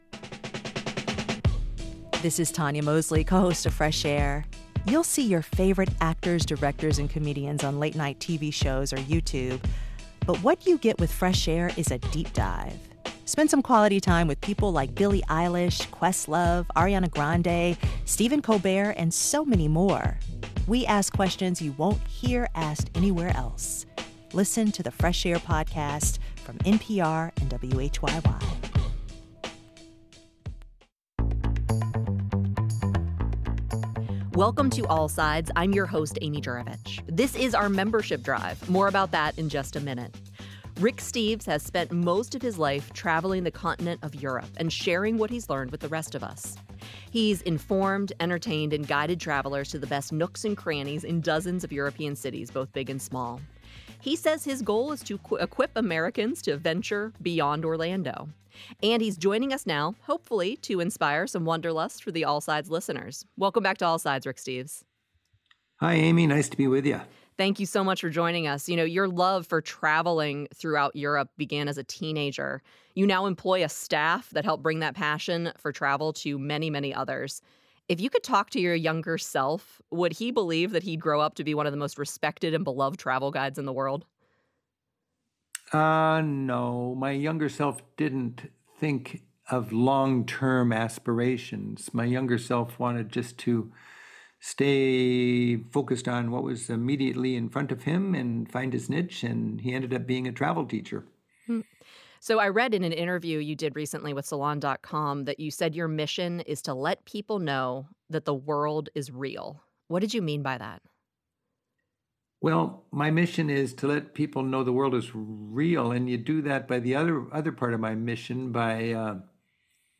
All Sides is a two-hour daily public affairs talk show designed to touch all sides of the issues and events that shape life in central Ohio.